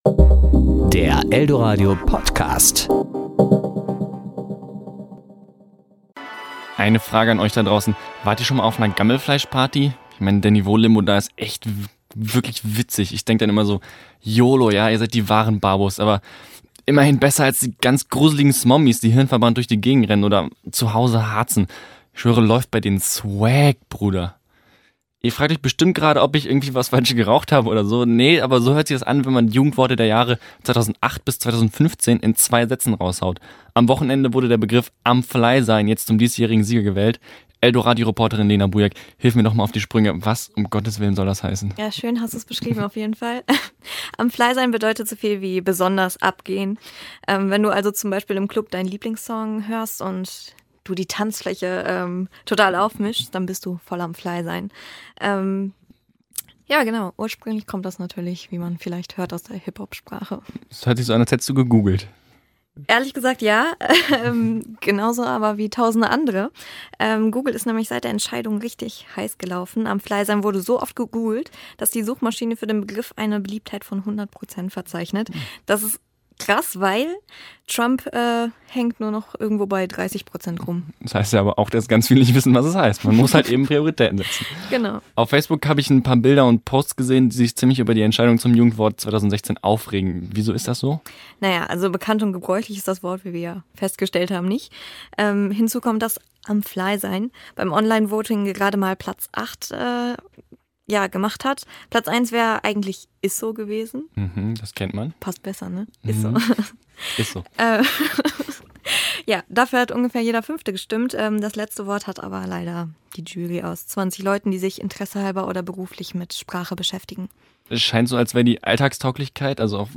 Serie: Kollegengespräch